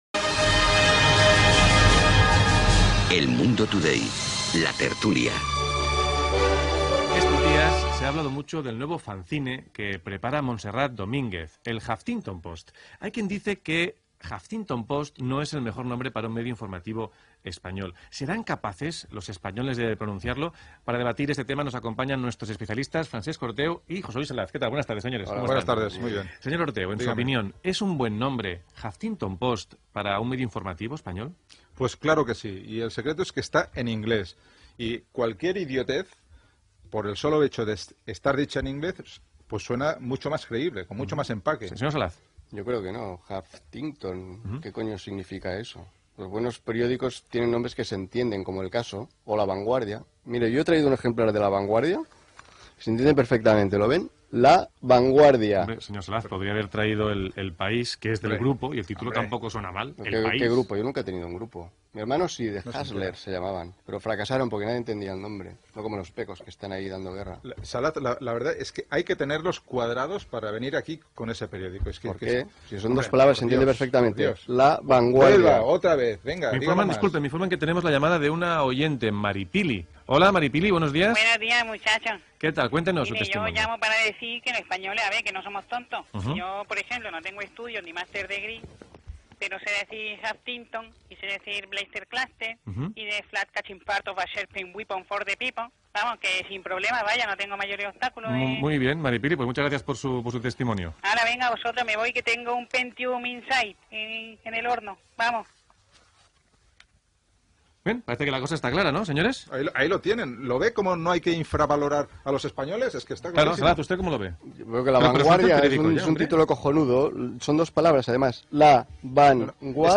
Careta de l'espai "La tertulia". Comentaris sobre el nom del mitjà de comunicació digital espanyol 'El Huffington Post'
Entreteniment